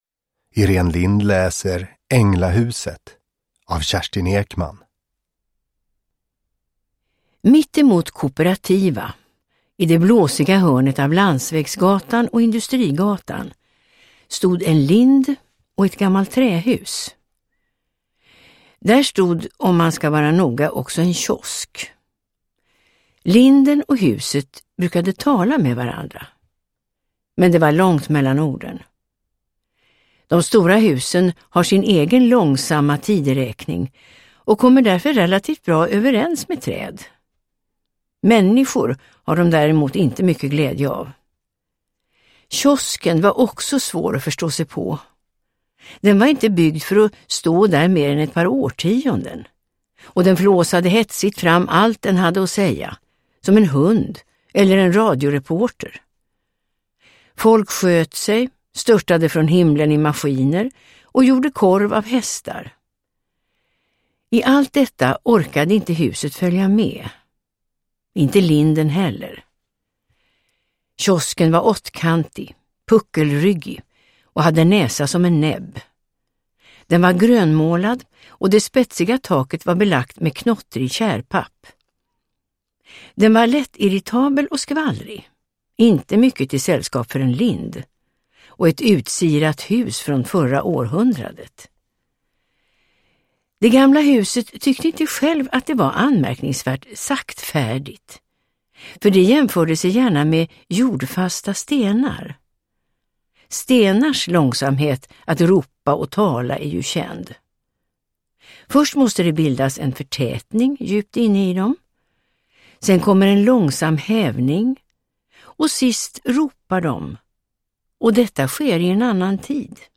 Änglahuset – Ljudbok – Laddas ner